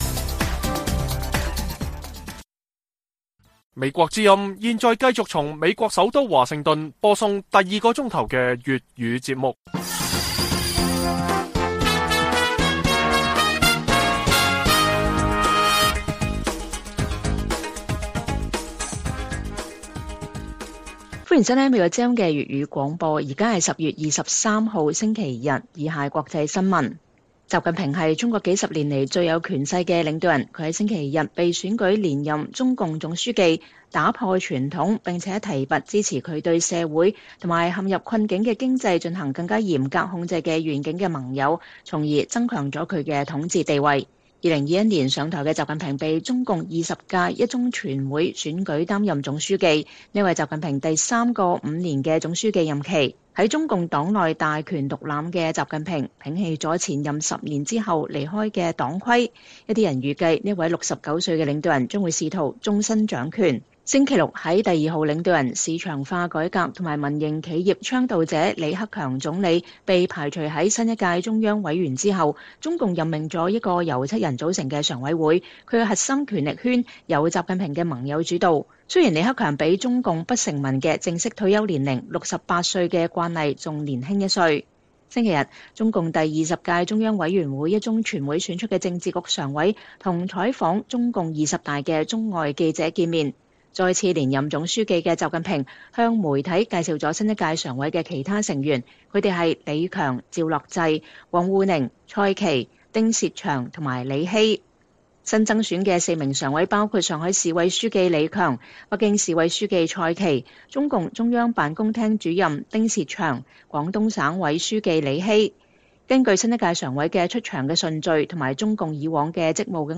粵語新聞 晚上10-11點: 親俄羅斯當局命令赫爾松居民立即撤離